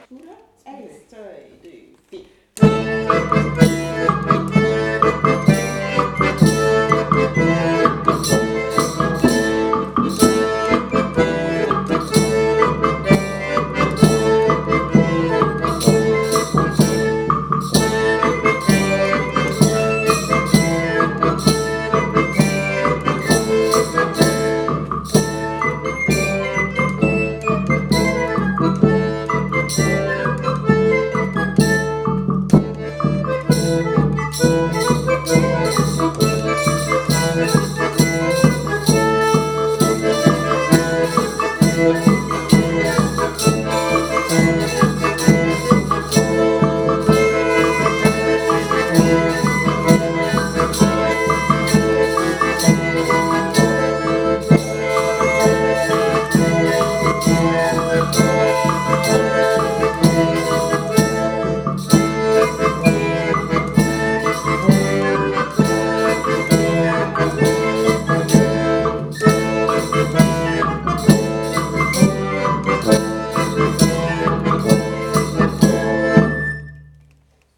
Bourée:
05-Bourre´e-sehr-schnell.m4a